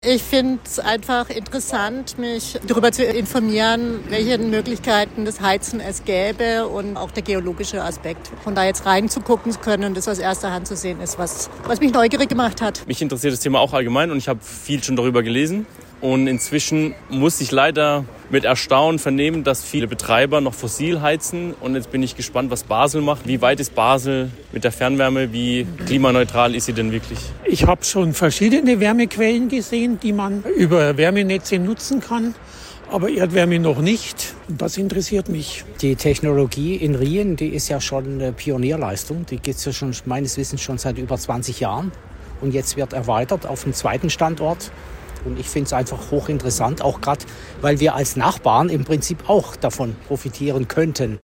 PopUp Studio Lörrach: Exkursion zum Thema Wärmewende nach Riehen
Stimmen von Teilnehmern: